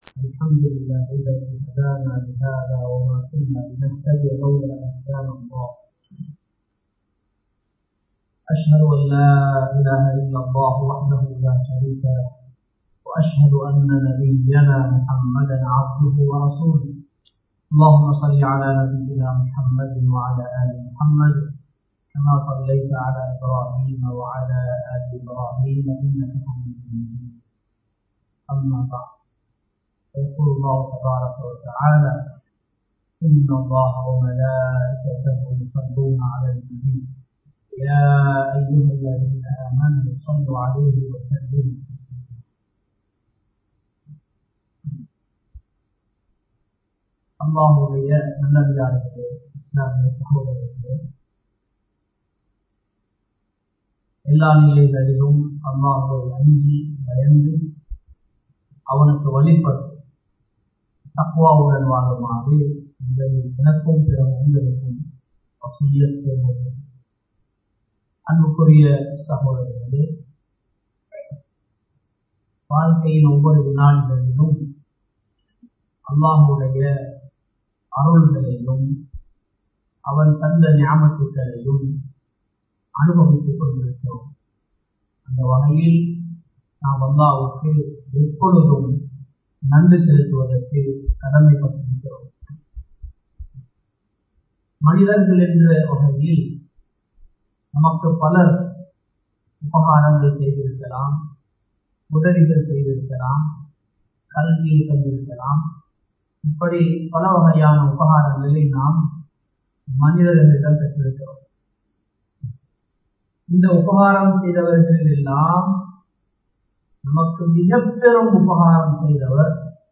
நபி மீது ஸலவாத் சொல்லுவதன் சிறப்புகள் | Audio Bayans | All Ceylon Muslim Youth Community | Addalaichenai
Muhideen (Markaz) Jumua Masjith